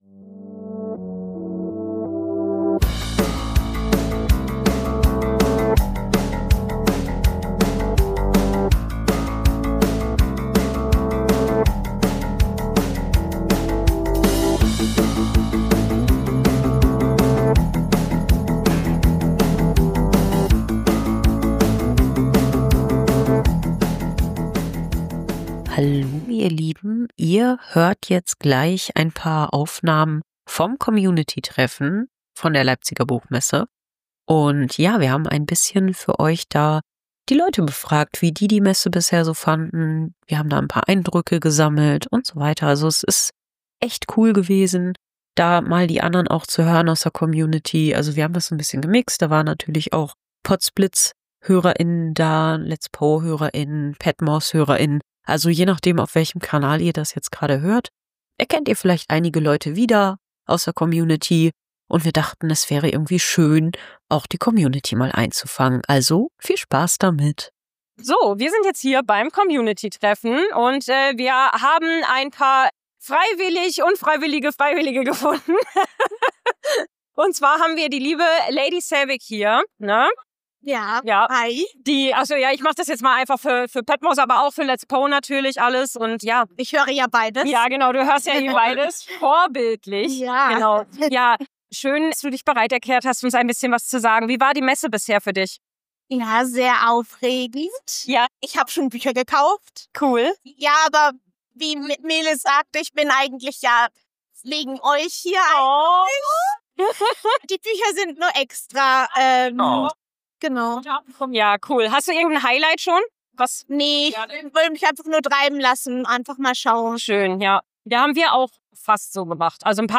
Theme und Quiz-Musik erstellt mit Udio AI (beta):